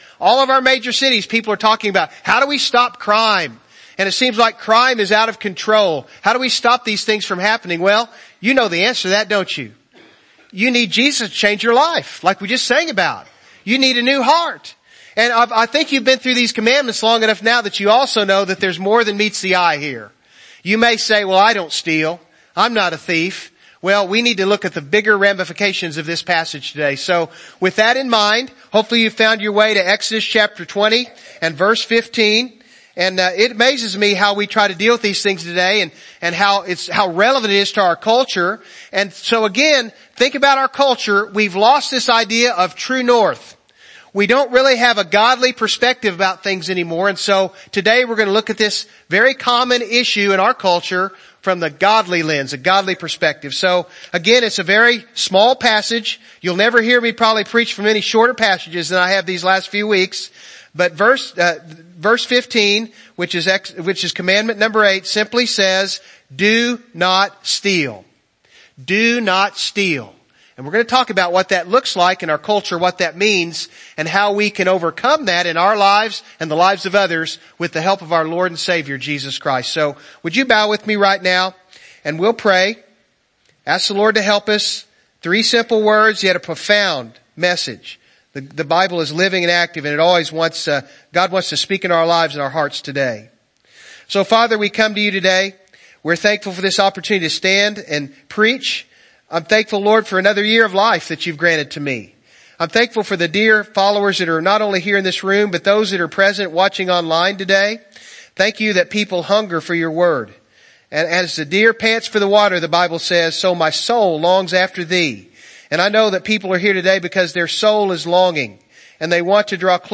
God's Top Ten Service Type: Morning Service « God’s Top Ten